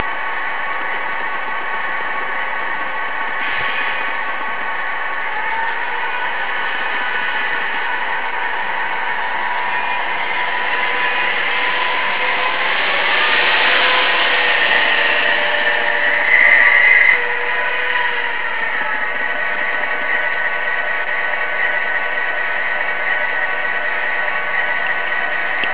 Il decoder non poteva essere che un Loksound e la scelta e' caduta sul Loksound con i suoni dell'americana F7.
L'unico inconveniente e' che ho il fischio e la campana della F7 invece della tromba svizzera, ma trovare un suono di tromba svizzera e riprogrammarlo non dovrebbe essere difficile.
Potete anche sentire il rombo di una sequenza di partenza seguita da una frenatura.